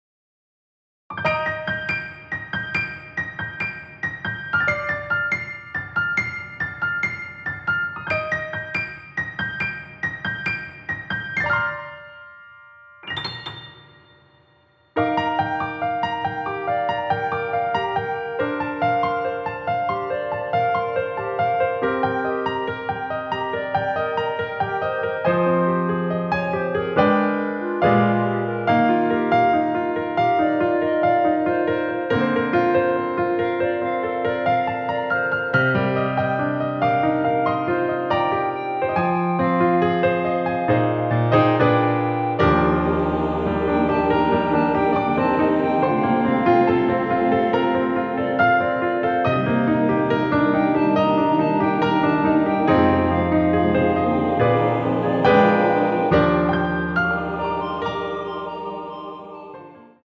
원키에서(+2)올린 멜로디 포함된 MR입니다.
Bb
앞부분30초, 뒷부분30초씩 편집해서 올려 드리고 있습니다.
중간에 음이 끈어지고 다시 나오는 이유는